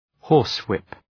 {‘hɔ:rs,wıp}